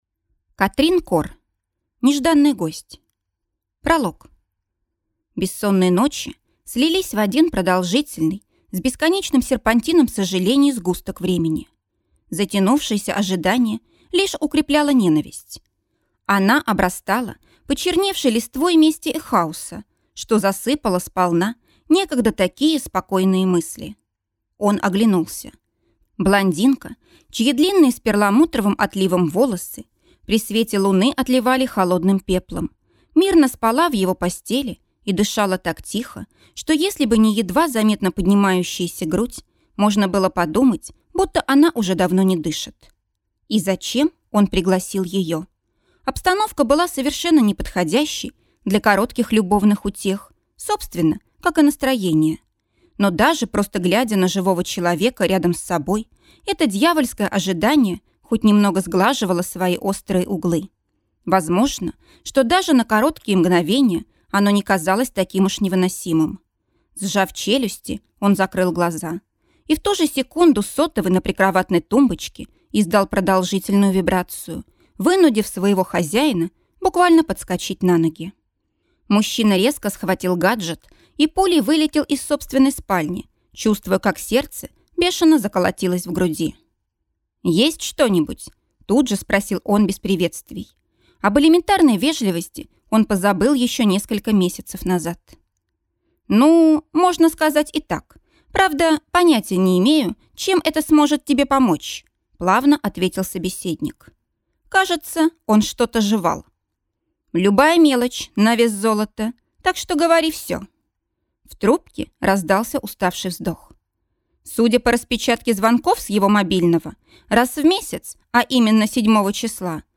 Аудиокнига Нежданный гость | Библиотека аудиокниг